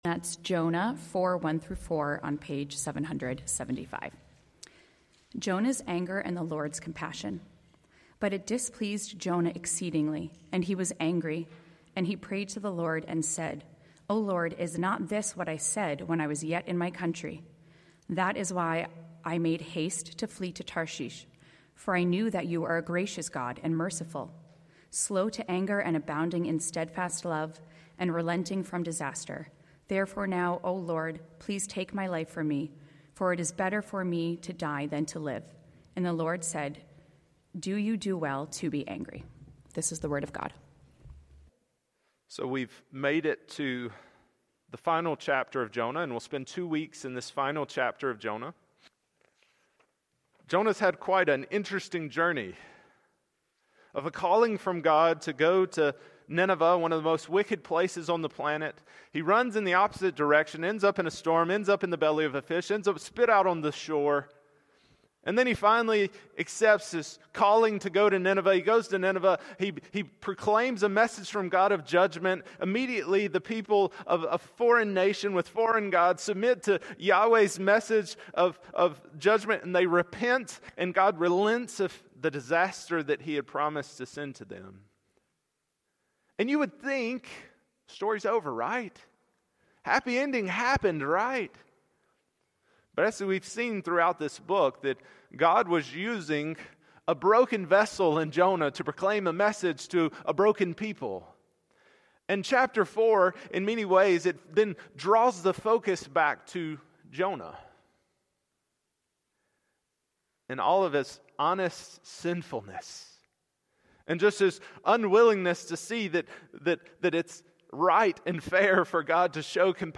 Passage: Jonah 4:1-4 Sermon